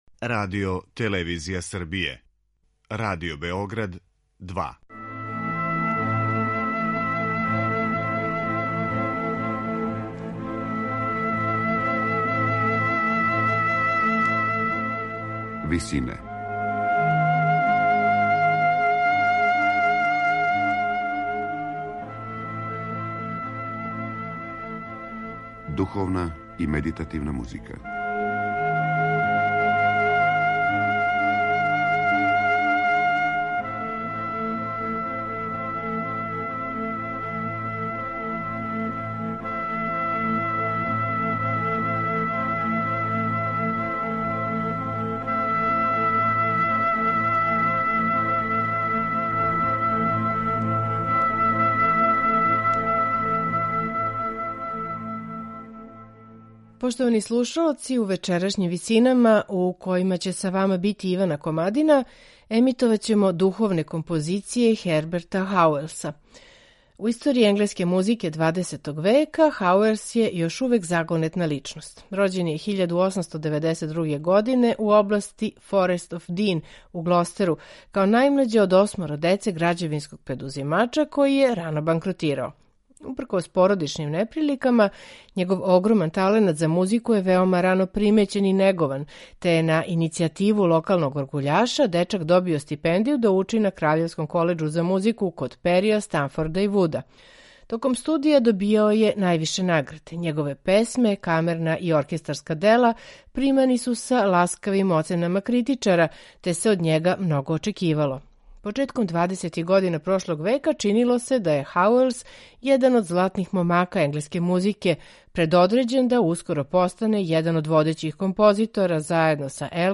духовна дела настала током шесте и седме деценије 20. века
оргуљаша